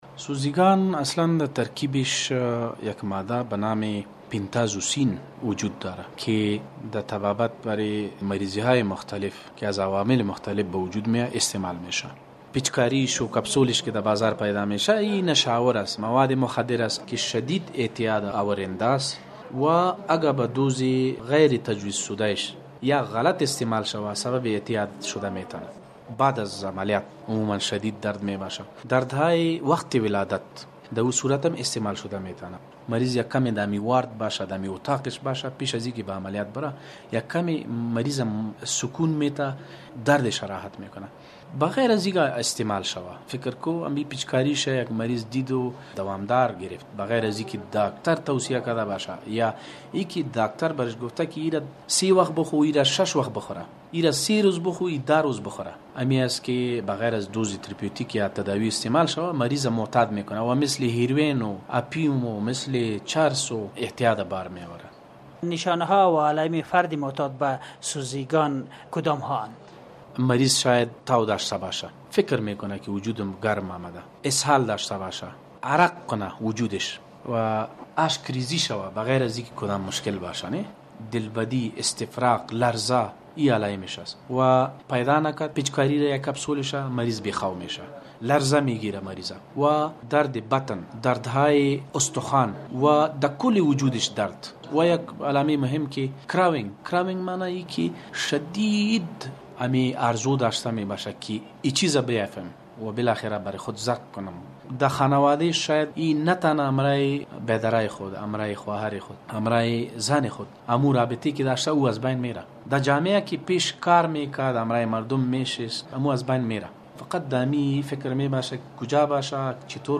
مصاحبه کاروان زهر